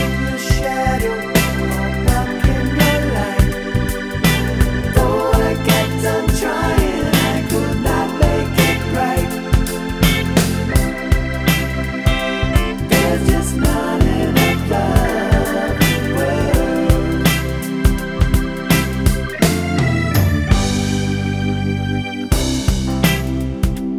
No Main Guitar Rock 3:45 Buy £1.50